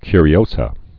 (kyrē-ōsə, -zə)